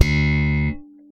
ALEM SLAP D2.wav